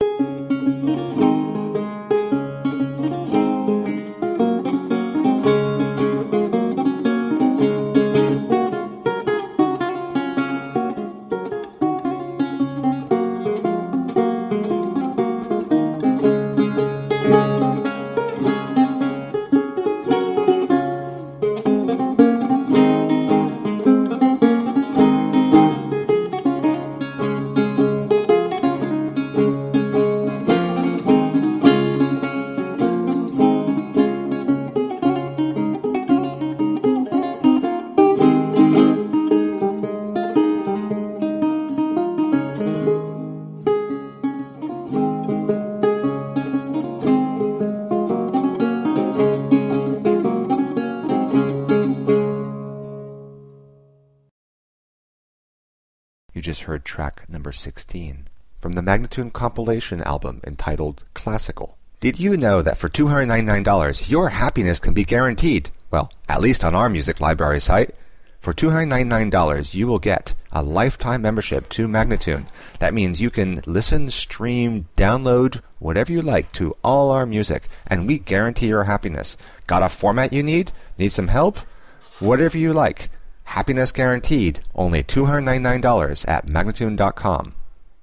Forlana